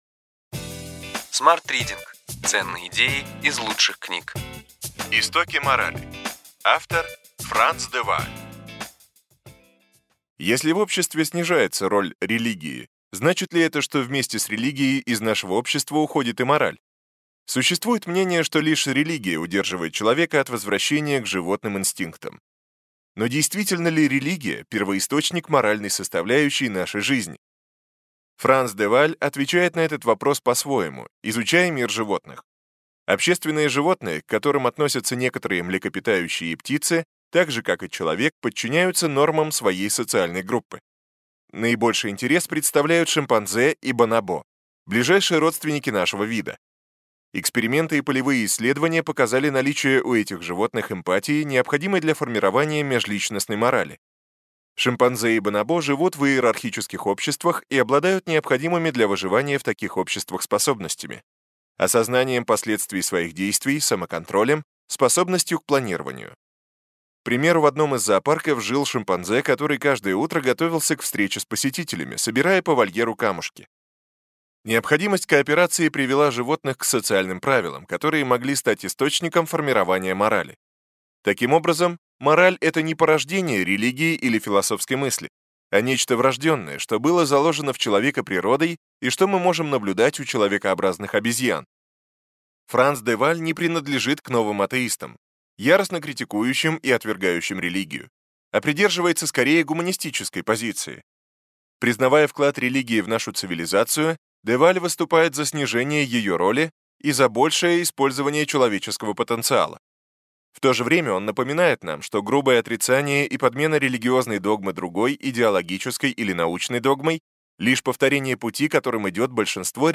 Аудиокнига Ключевые идеи книги: Истоки морали. В поисках человеческого у приматов.